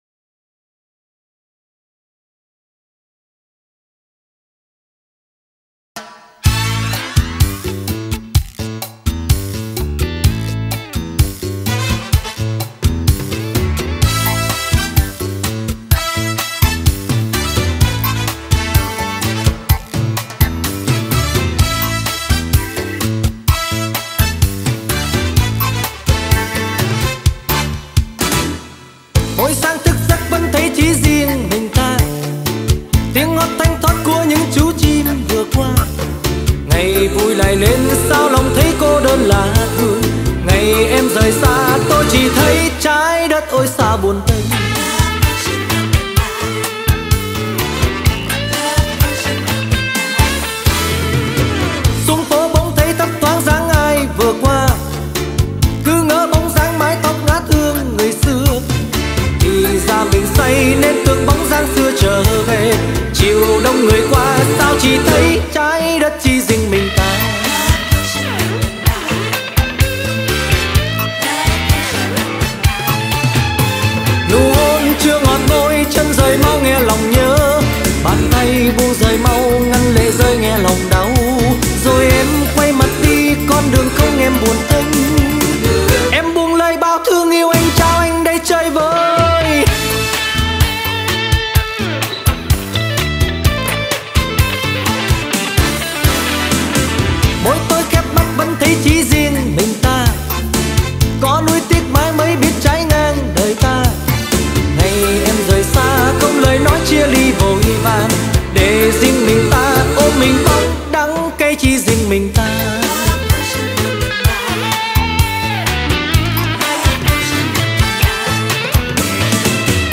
nhạc sống hà tây